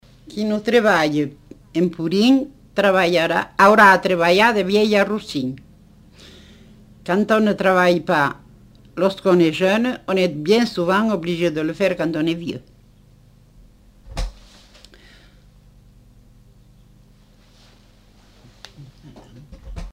Aire culturelle : Comminges
Lieu : Montauban-de-Luchon
Type de voix : voix de femme
Production du son : récité
Classification : proverbe-dicton